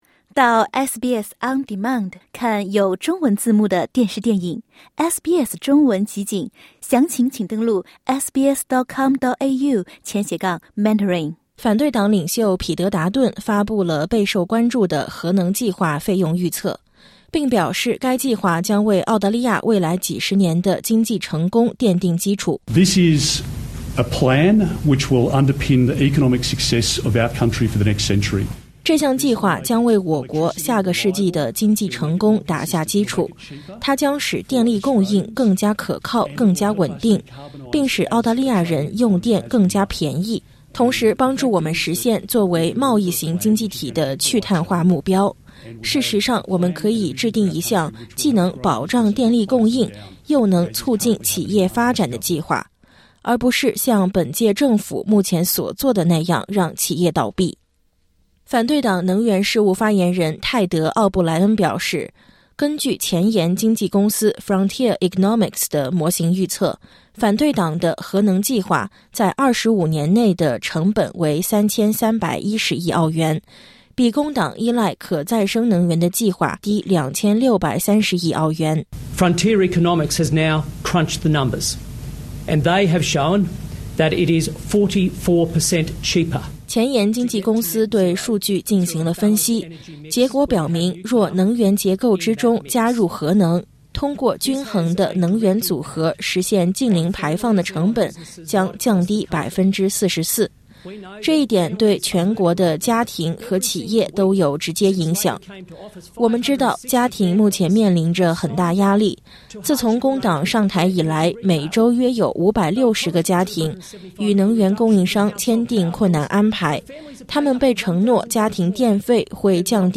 点击音频，收听综合报道。